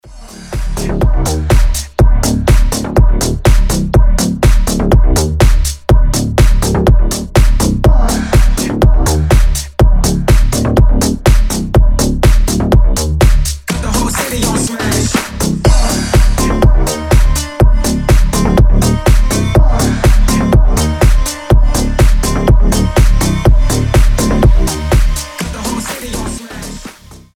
Громкие Крутые Драйвовые